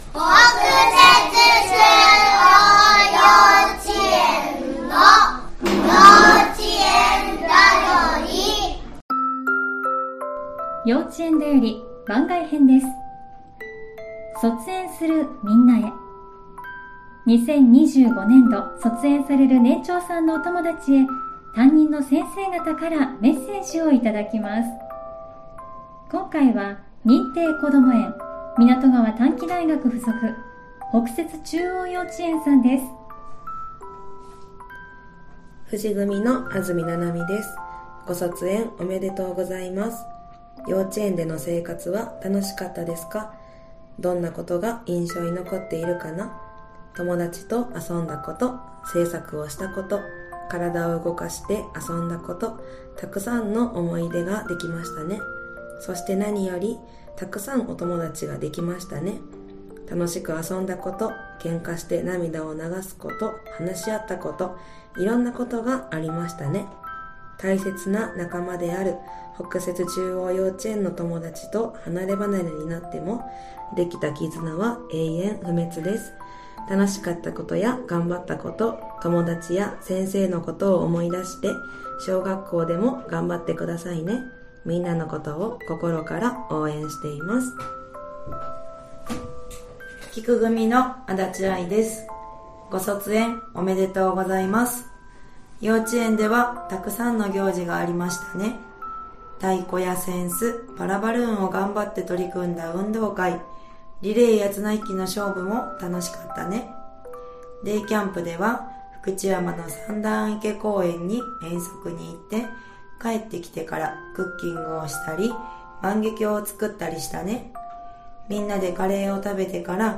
2025年度卒園する年長さんへ、先生方からのメッセージをお届けします！